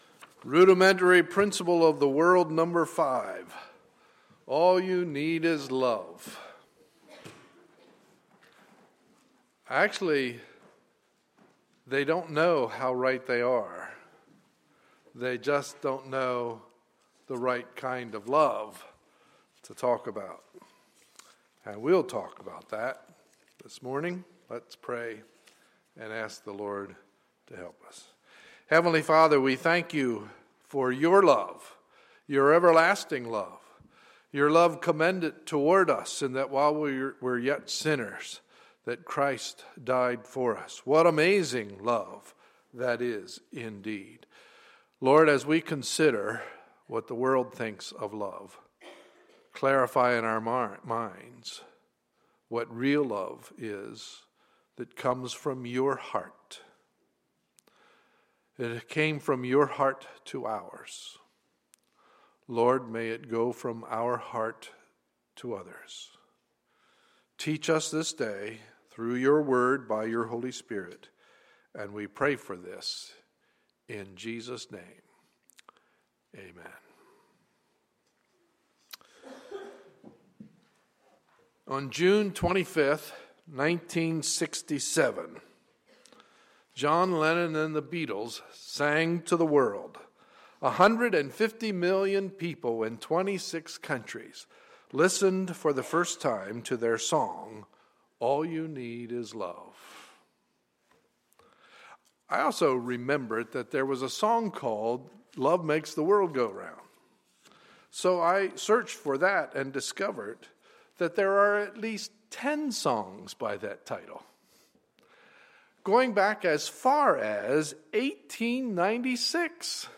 Sunday, April 6, 2014 – Morning Service